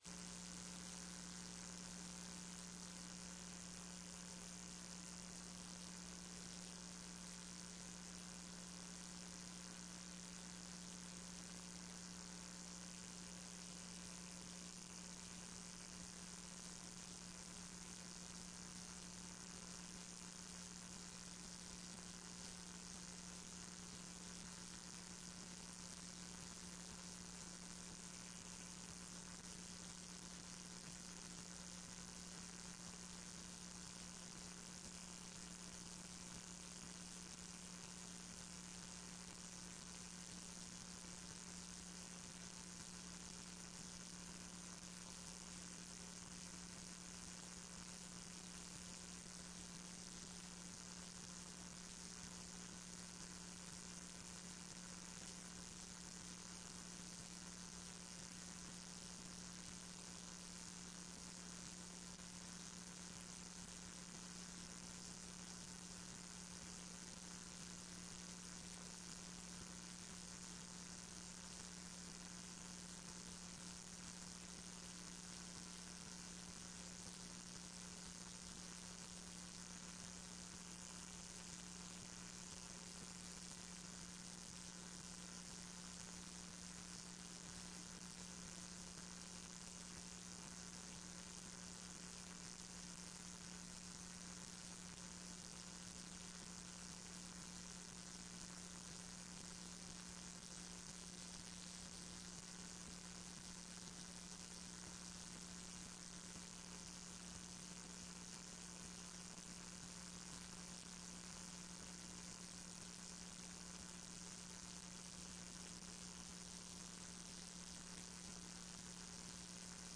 TRE-ES - Áudio da sessão 07.10.14